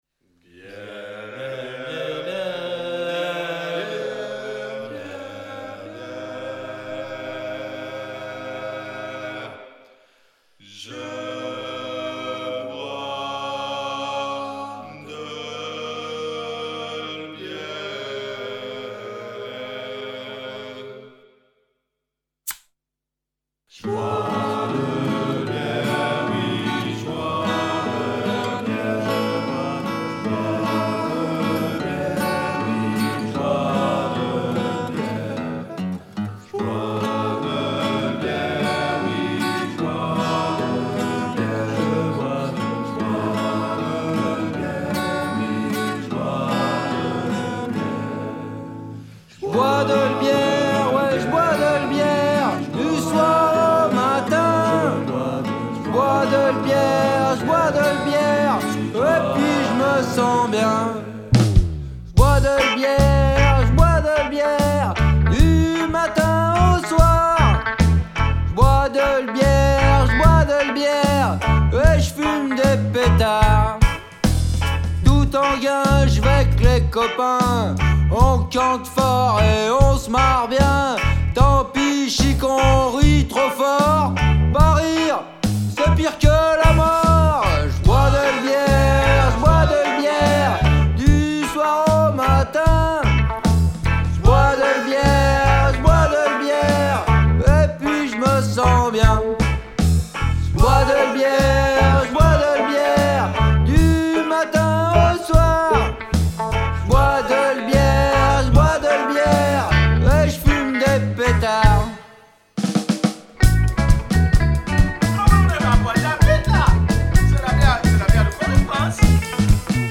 Genre : Punk.